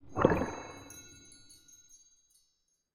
Minecraft Version Minecraft Version snapshot Latest Release | Latest Snapshot snapshot / assets / minecraft / sounds / mob / glow_squid / ambient4.ogg Compare With Compare With Latest Release | Latest Snapshot
ambient4.ogg